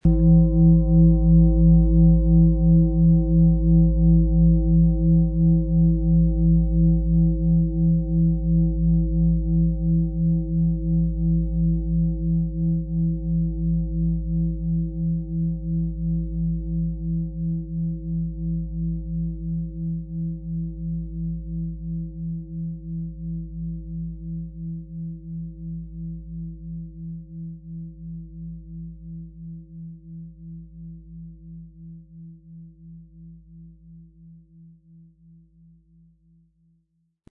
XXXL Fußreflexzonen-Klangschale – intensive Schwingungen für tiefgehende Entspannung
Unter dem Artikel-Bild finden Sie den Original-Klang dieser Schale im Audio-Player - Jetzt reinhören.
Durch die traditionsreiche Herstellung hat die Schale diesen einmaligen Ton und das besondere, bewegende Schwingen der traditionellen Handarbeit.
Sanftes Anspielen mit dem gratis Klöppel zaubert aus Ihrer Schale berührende Klänge.
MaterialBronze